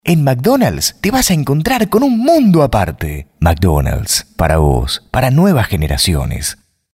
LOCUTOR PREMIUM
OBSERVACIONES: Voz versátil para todo tipo de grabaciones en diversos idiomas.
DEMO VOZ JOVEN: